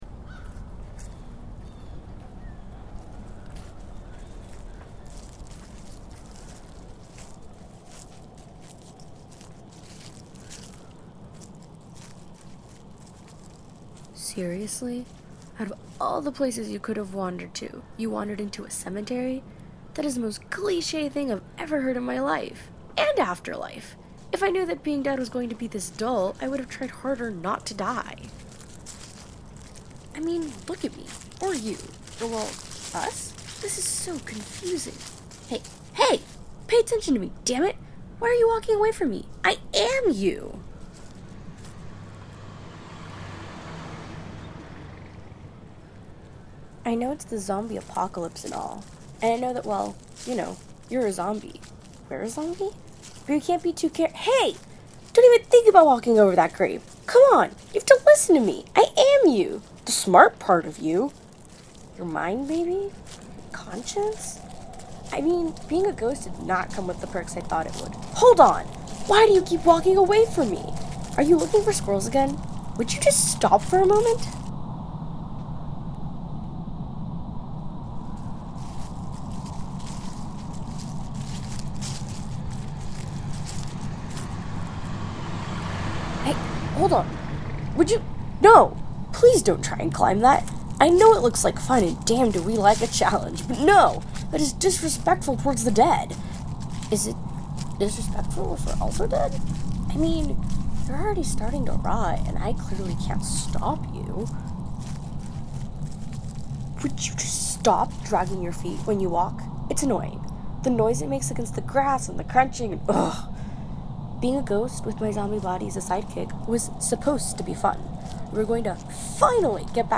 Audio Walk